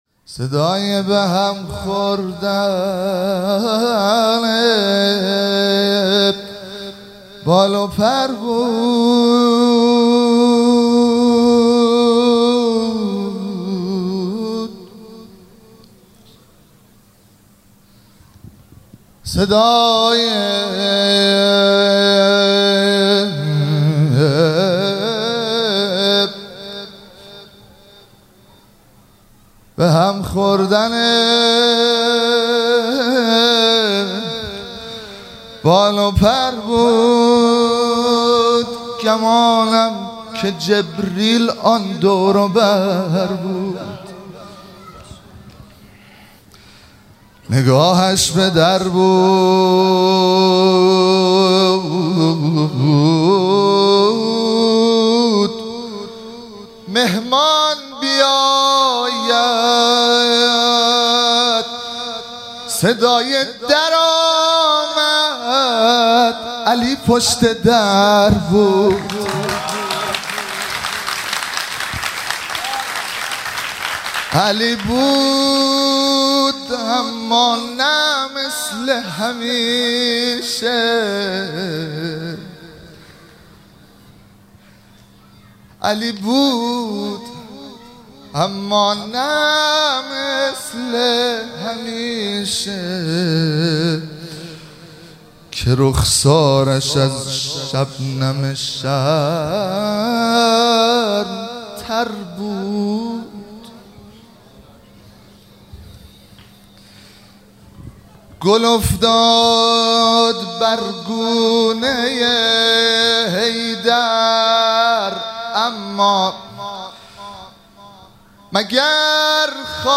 مراسم جشن سالگرد ازدواج حضرت امیرالمومنین علی علیه السلام و حضرت فاطمه زهرا سلام الله علیها- خرداد 1402
مدح- صدای به هم خوردن بال و پر بود